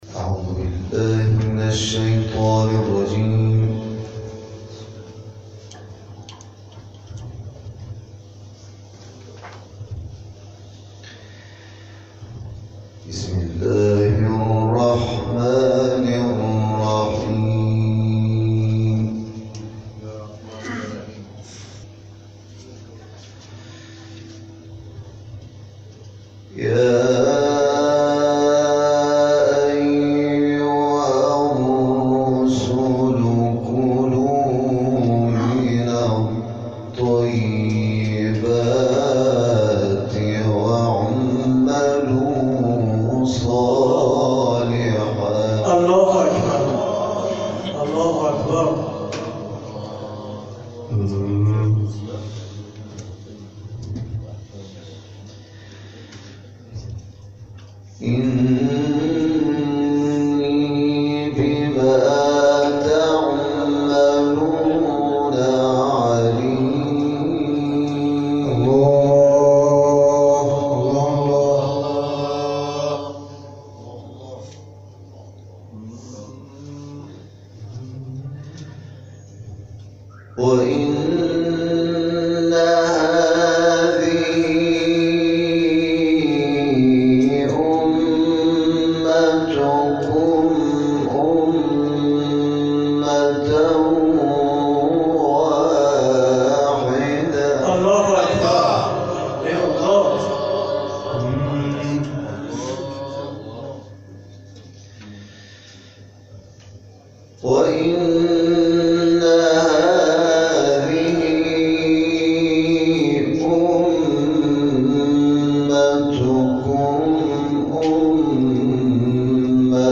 جدیدترین تلاوت
در ادامه این تلاوت ارائه می‌شود.